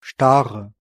Ääntäminen
Ääntäminen Tuntematon aksentti: IPA: /ˈʃtaːʀə/ Haettu sana löytyi näillä lähdekielillä: saksa Käännöksiä ei löytynyt valitulle kohdekielelle. Stare on sanan Star monikko.